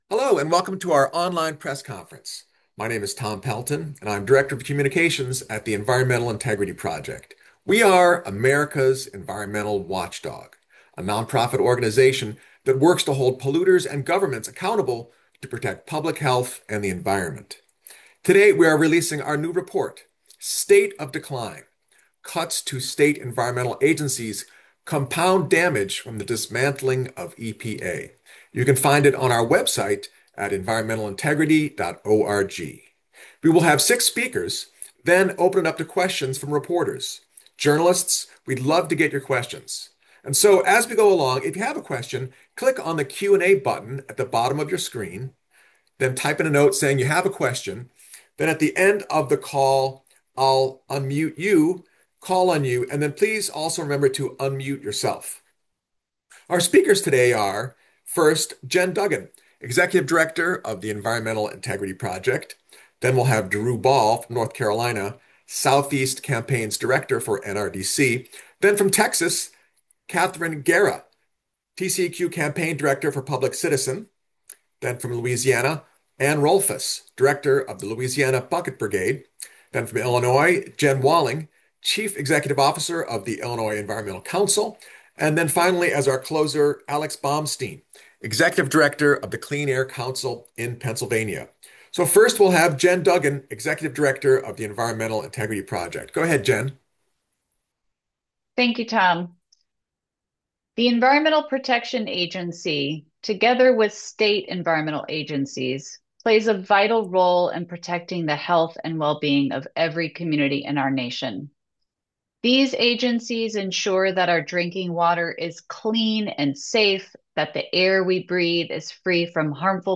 For audio of the press conference, click here and here.